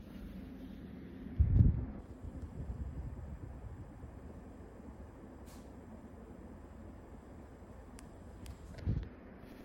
Je vais refaire un enregistrement avec mon téléphone en mode Dictaphone, ainsi qu'avec un split qui n'a pas ce bruit.
J’ai effectué 2 capture de sons une avec le split dans le salon FTXM50 qui ne présente pas de soucis.
split-salon-ftxm50.mp3